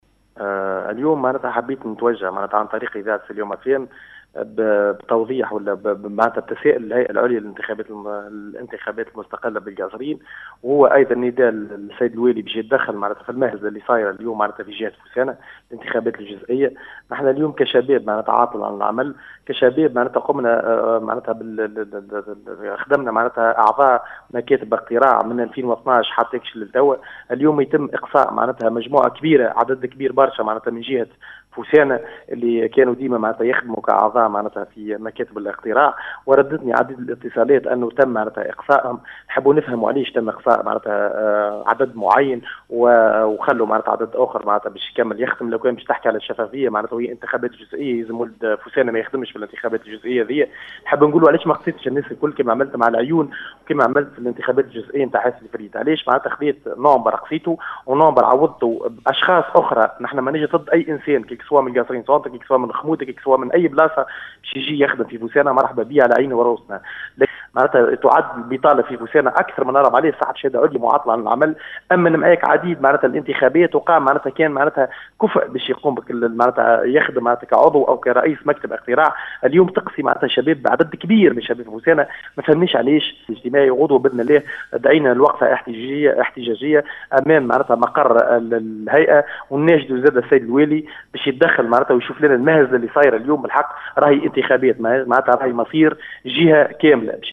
في اتصال براديو السيليوم اف ام